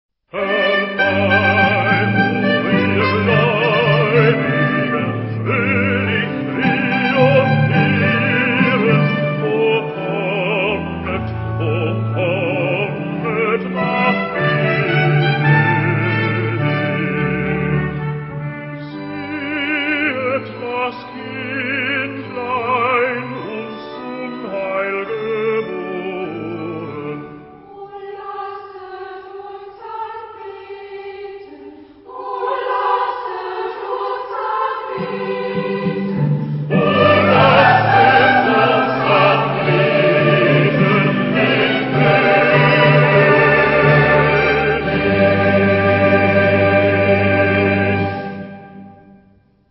Genre-Style-Form: Sacred
Mood of the piece: festive
Type of Choir: SATB  (1 mixed voices )
Instruments: Melody instrument (1) ; Keyboard (1)
Tonality: G major